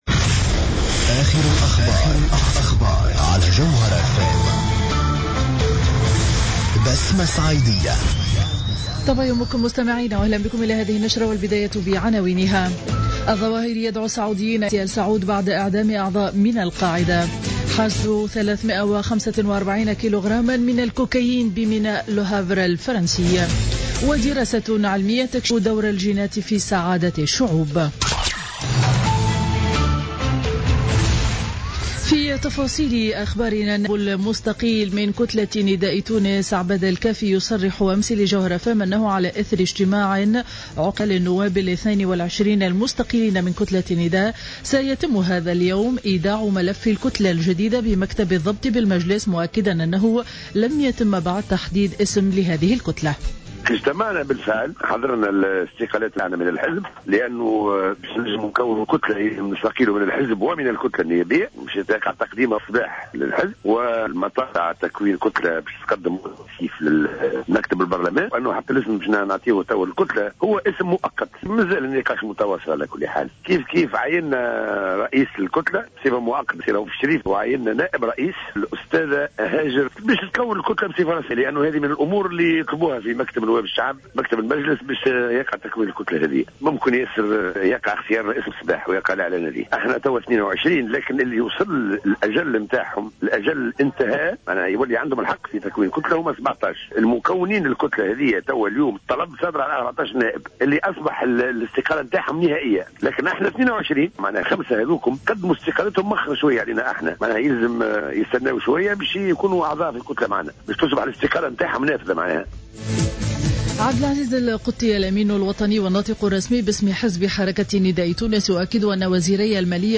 نشرة أخبار السابعة صباحا ليوم الجمعة 15 جانفي 2016